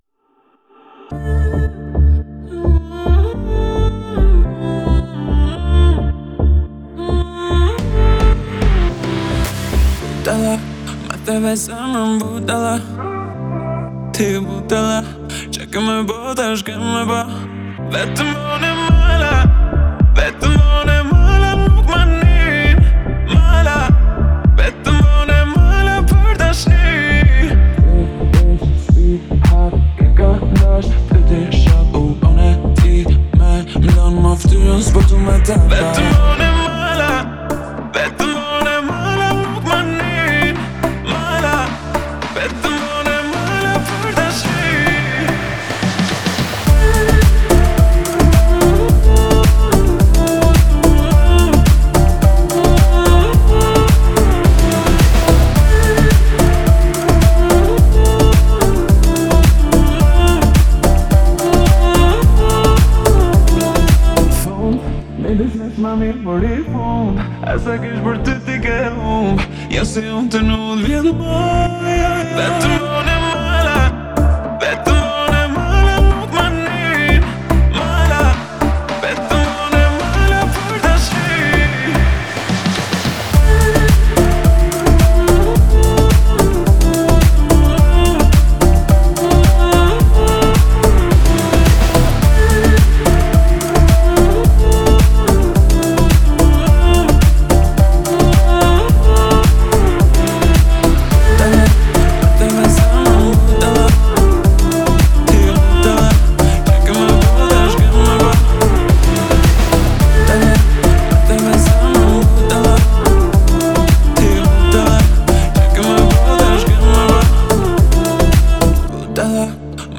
идеально подходя для клубной атмосферы.